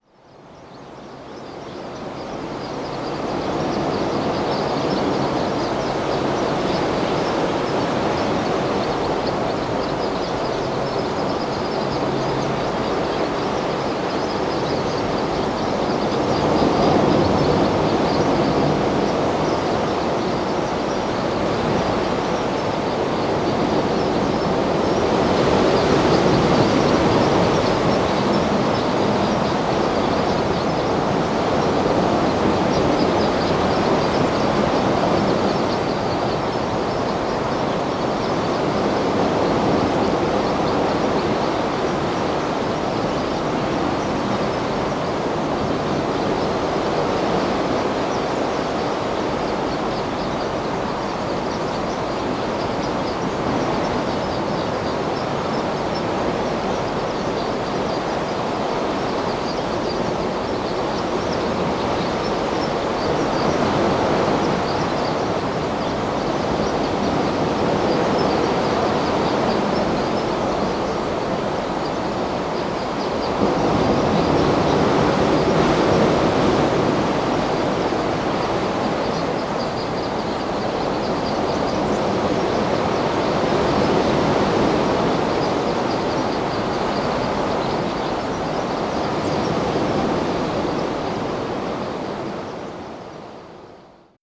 Recordings from Juan de Fuca Provincial Park
58. Red Crossbill flock chatter with Botanical Beach waves